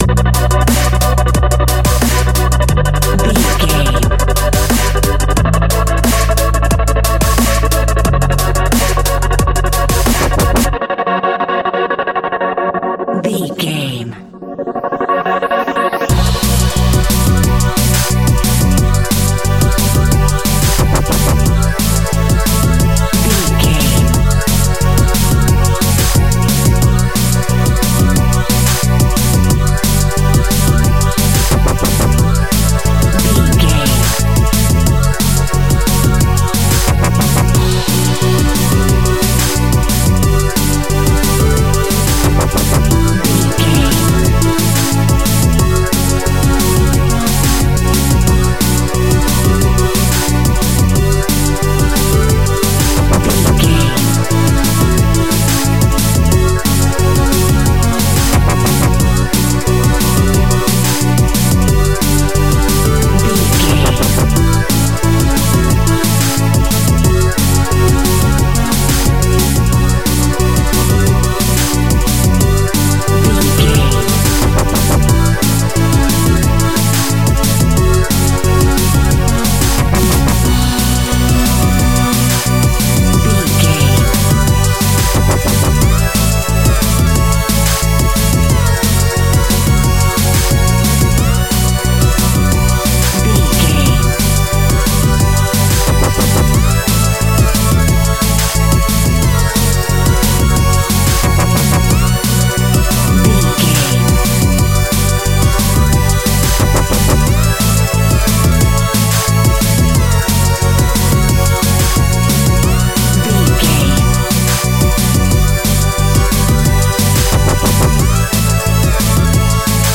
A great piece of royalty free music
Fast paced
Aeolian/Minor
aggressive
dark
driving
energetic
drum machine
synthesiser
sub bass
synth leads
synth bass